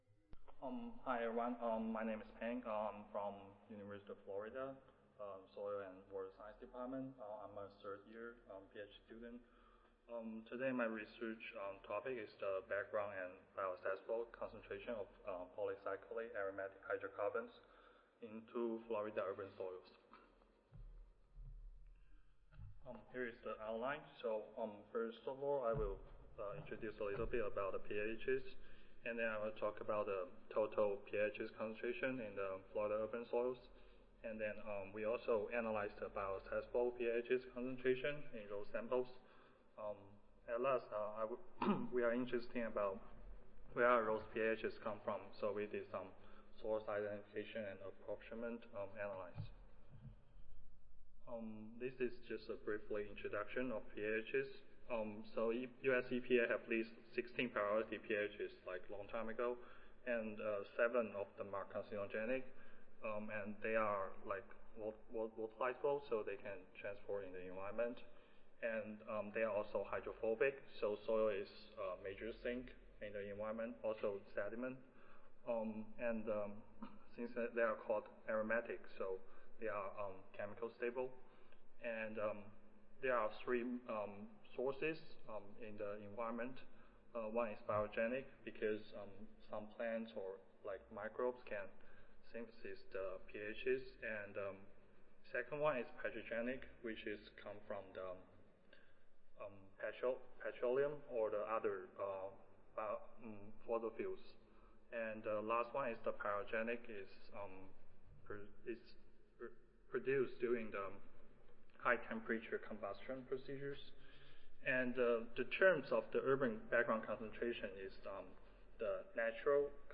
Session: Soil Chemistry General Oral (ASA, CSSA and SSSA International Annual Meetings)
Nanjing University/University of Florida Audio File Recorded Presentation